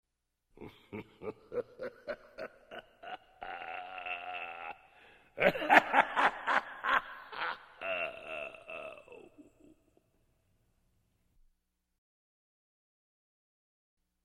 Зловеще усмехается